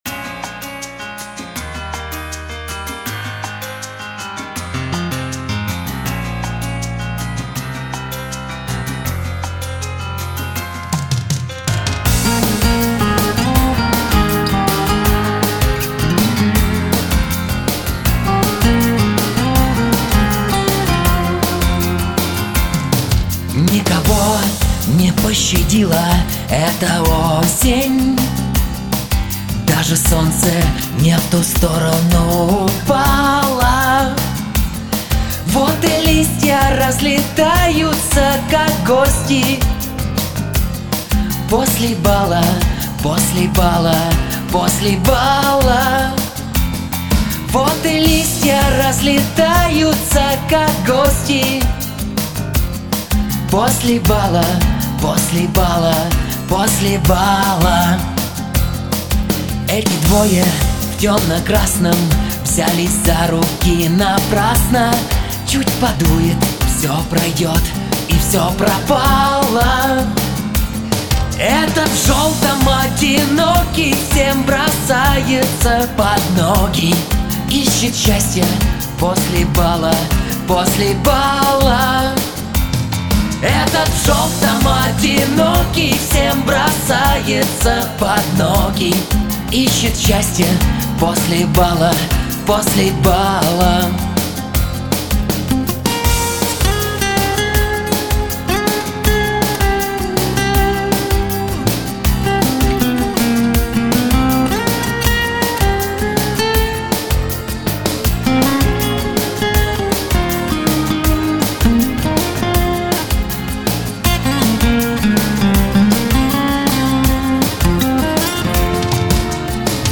Гитара / Лирические
Слушать на гитаре